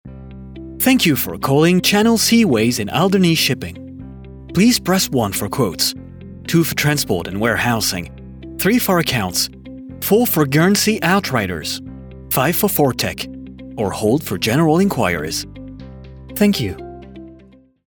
Englisch (International)
Natürlich, Vielseitig, Zuverlässig, Freundlich, Corporate
Erklärvideo